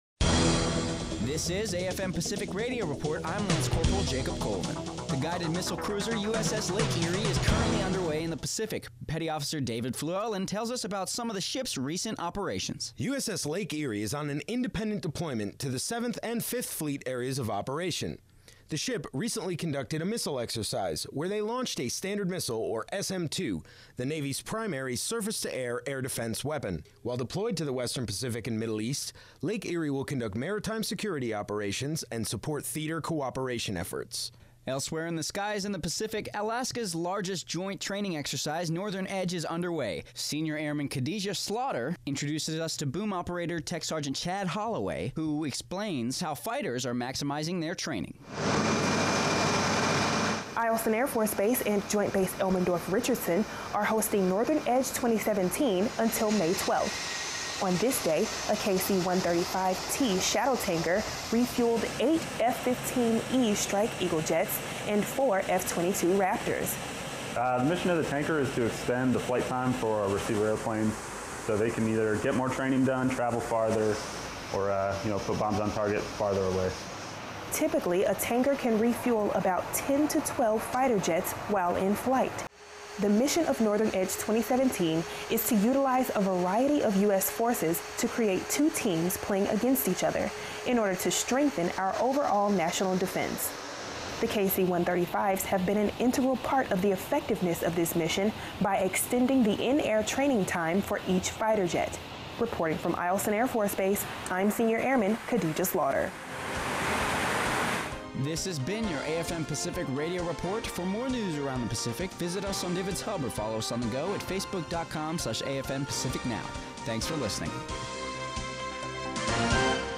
Pacific Radio Report